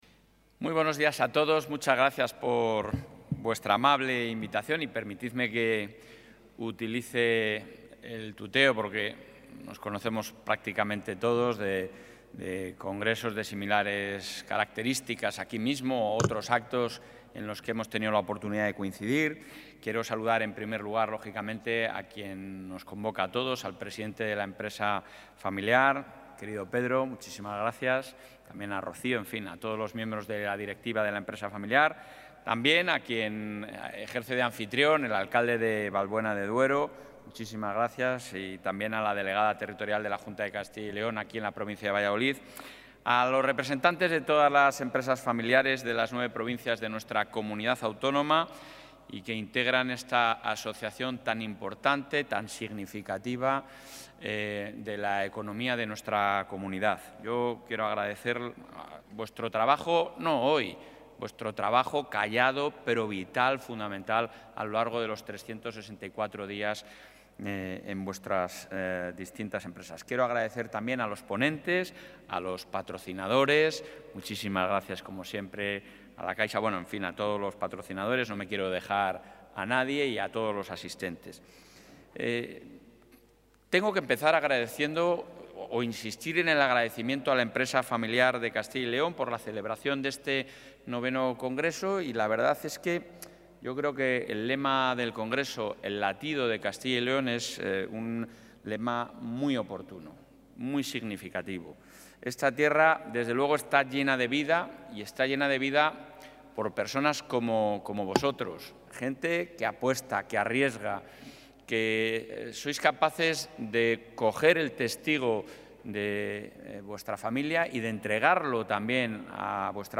Intervención del presidente.
El presidente de la Junta ha participado esta mañana en el IX Congreso Regional de la Empresa Familiar, que reúne en la localidad vallisoletana de San Bernardo a los responsables de las principales empresas familiares de la Comunidad.